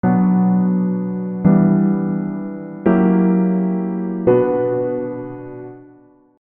Bm - Em7 - F#9 - Bm
Chords: Bm - Em7 - F#9 - Bm